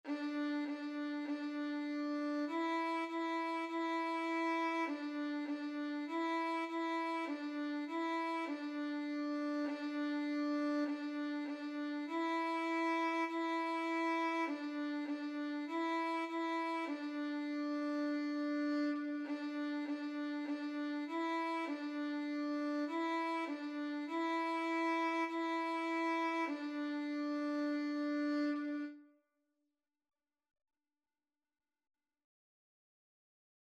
4/4 (View more 4/4 Music)
D5-E5
Violin  (View more Beginners Violin Music)
Classical (View more Classical Violin Music)